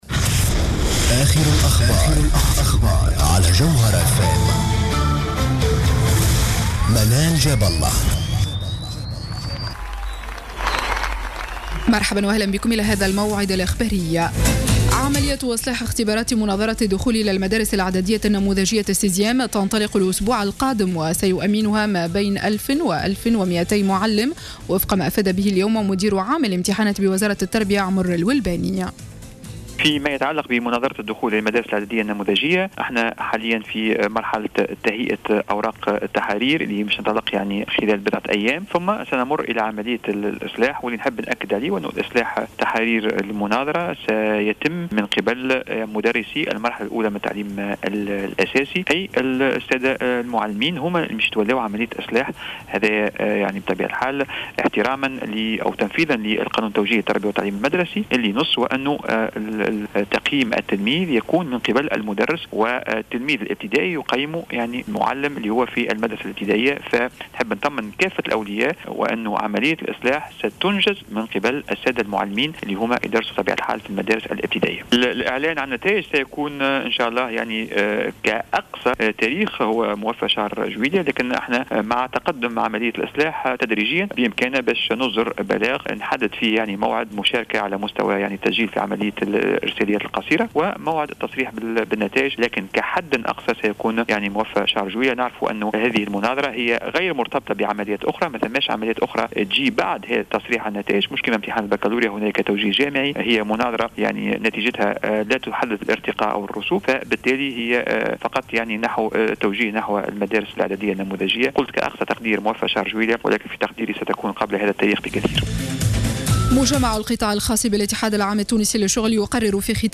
نشرة أخبار الخامسة مساء ليوم الثلاثاء 23 جوان 2015